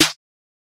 Snare (sdp interlude).wav